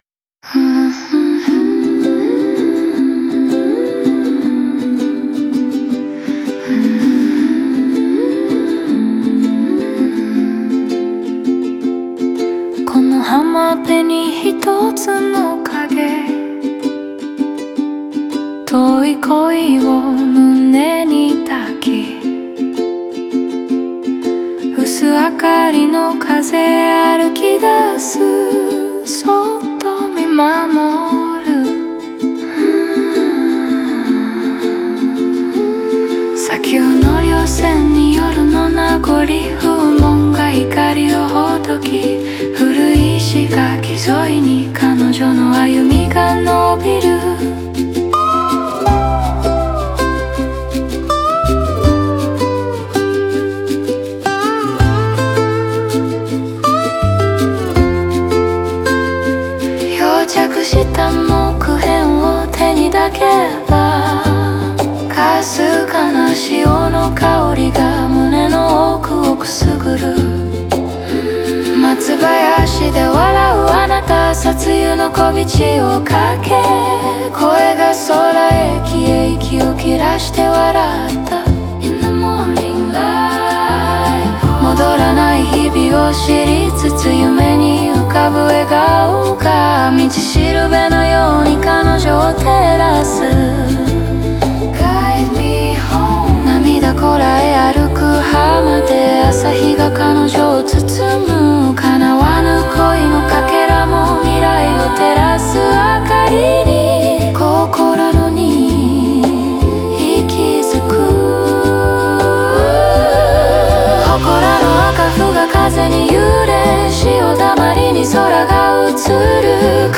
オリジナル曲♪
英語のバックコーラスは情緒の余韻として自然に溶け込み、曲全体の流れに沿って夢幻的かつ映画的なムードを作り出す。